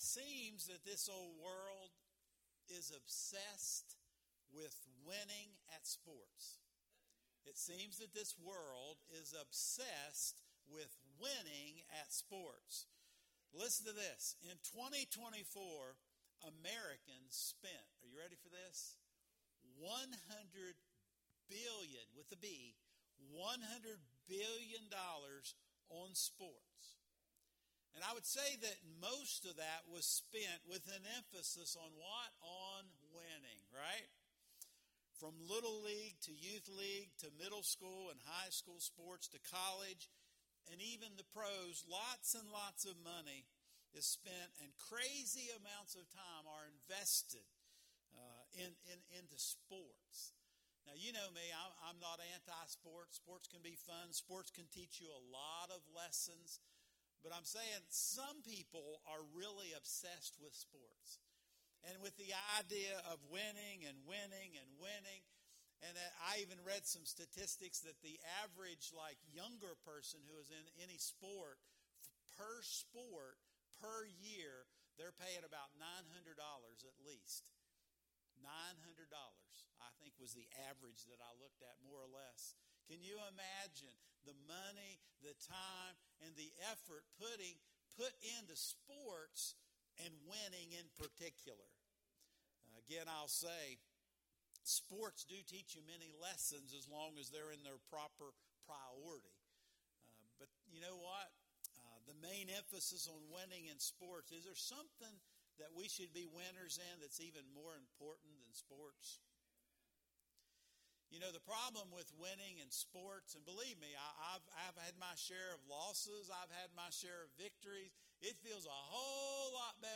Sermons | Oak Mound Evangelical Church